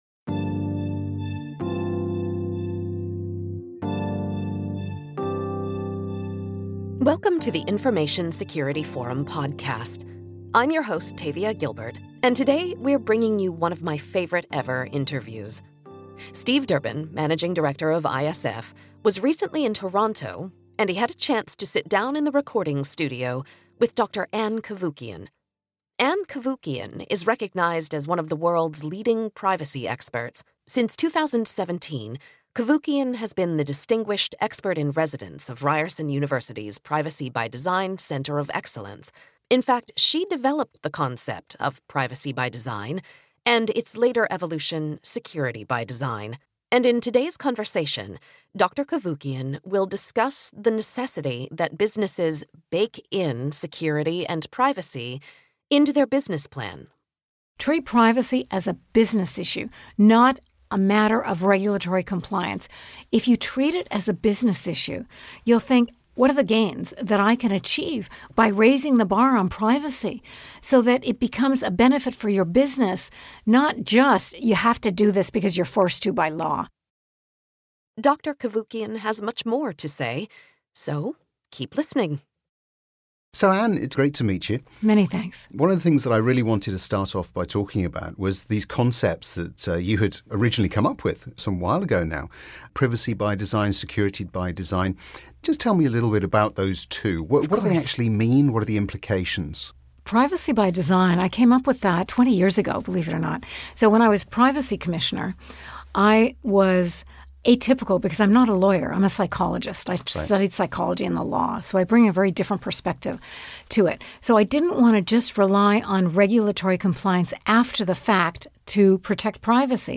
In fact, Ann developed the concept of Privacy by Design and its later evolution, Security by Design. In today's conversation, Dr. Cavoukian will discuss the necessity that businesses "bake in" security and privacy into their business plan.